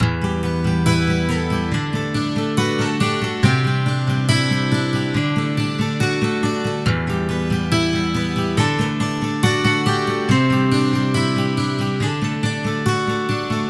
情感吉他钢琴
描述：非常悲伤
标签： 149 bpm RnB Loops Guitar Electric Loops 2.17 MB wav Key : Unknown
声道立体声